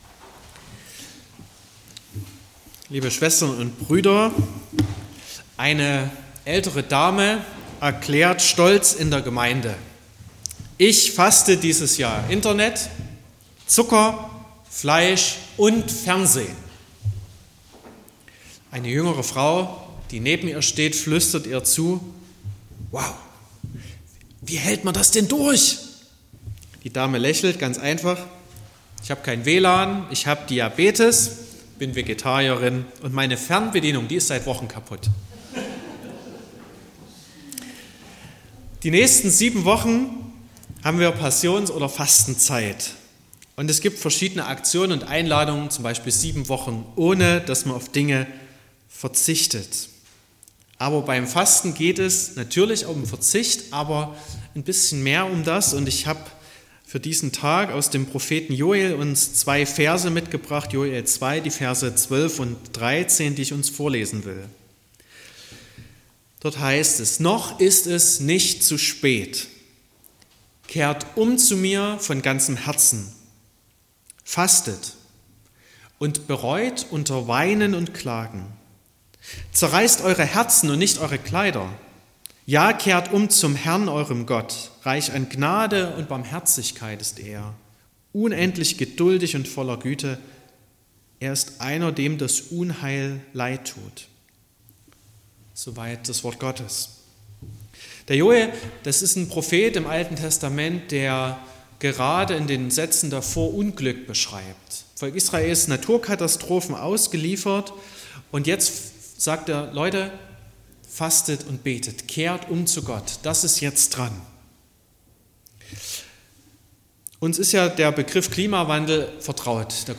05.03.2025 – gemeinsame Andacht zum Frühjahrsbußtag
Predigt und Aufzeichnungen